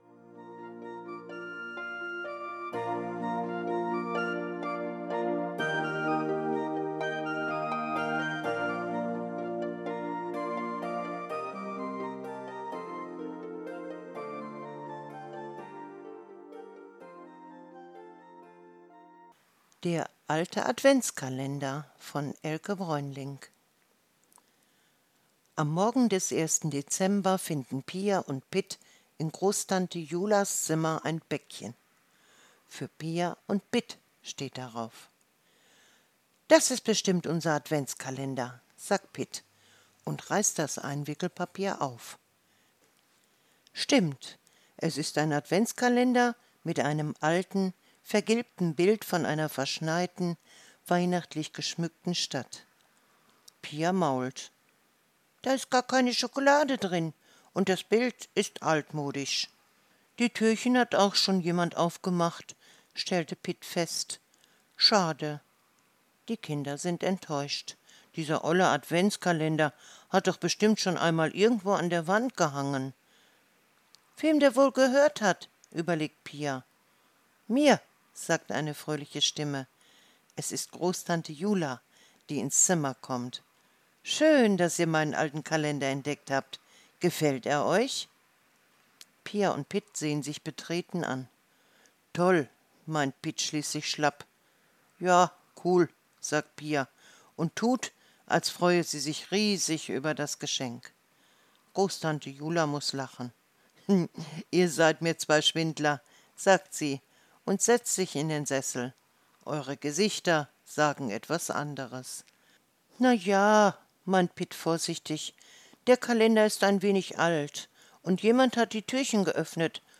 Adventsgeschichte